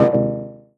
lose.wav